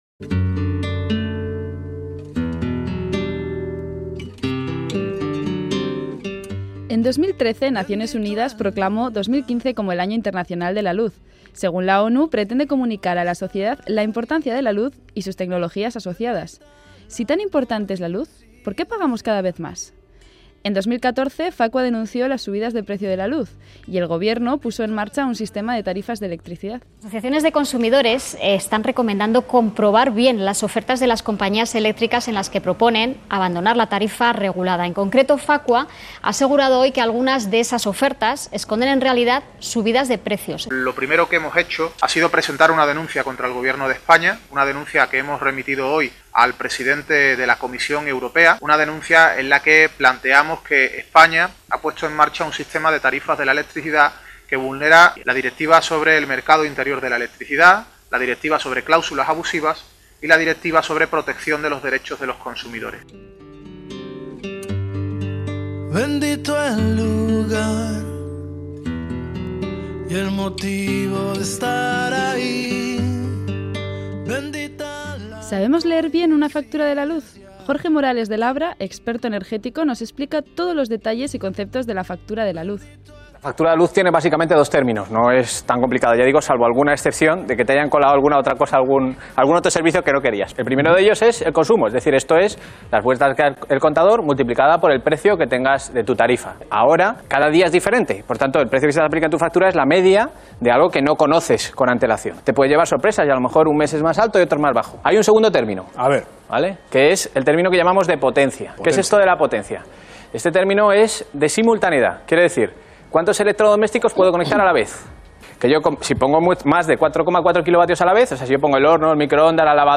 Reportaje el precio de la luz | Más que Palabras radio Euskadi